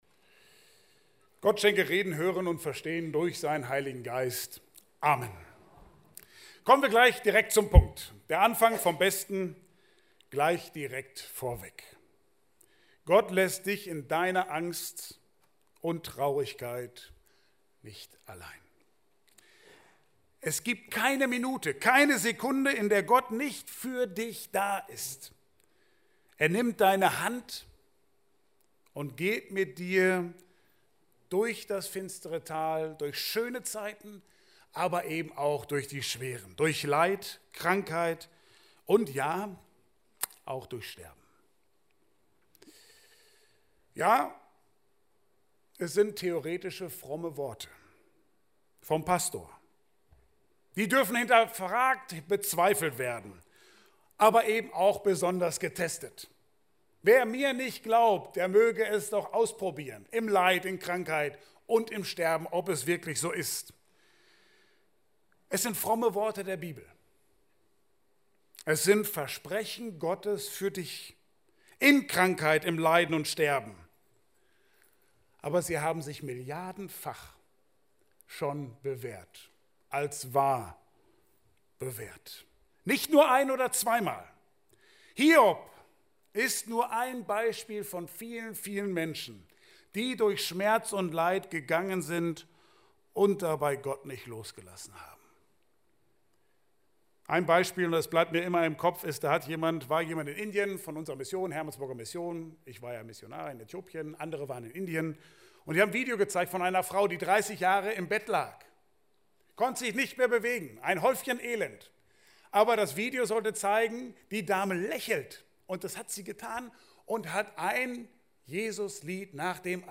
Bibelstelle: Hiob 19,25 Dienstart: Gottesdienst